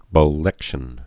(bō-lĕkshən)